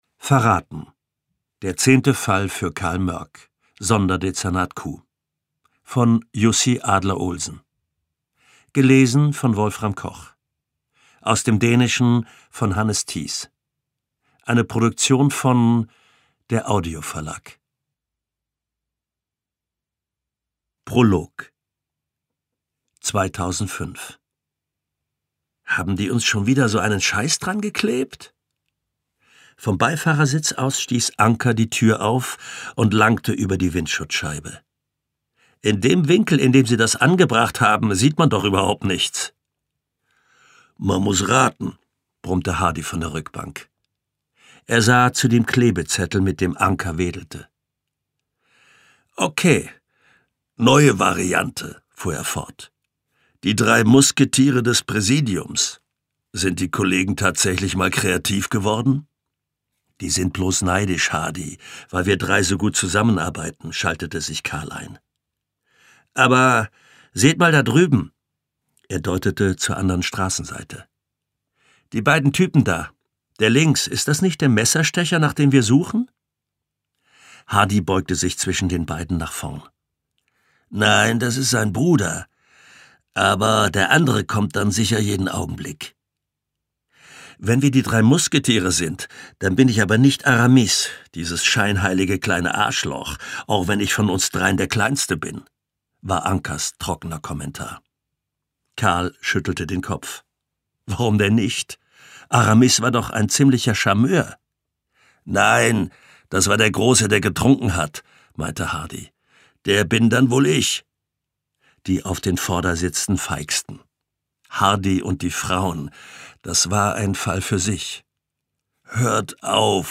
Wolfram Koch (Sprecher)